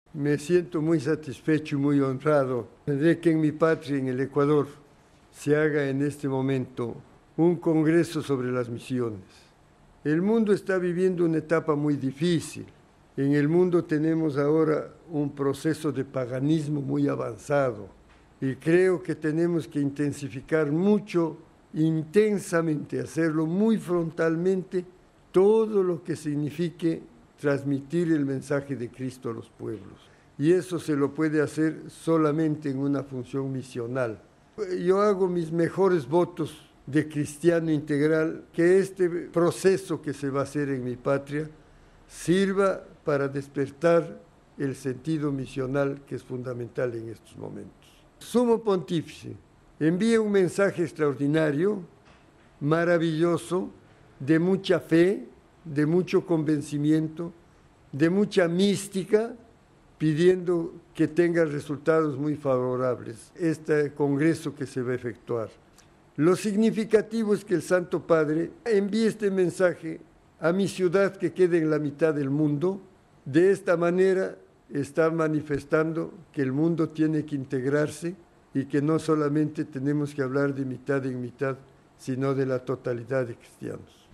Entrevista al embajador de Ecuador ante la Santa Sede sobre los Congresos misioneros latinoamericanos y las olimpiadas de Pekín